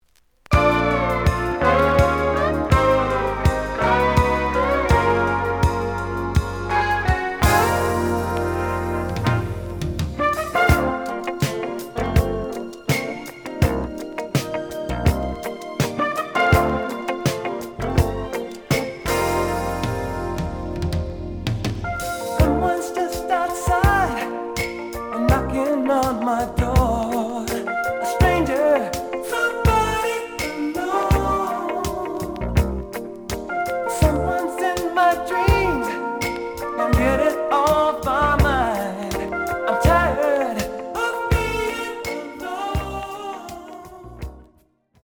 The audio sample is recorded from the actual item.
●Genre: Jazz Rock / Fusion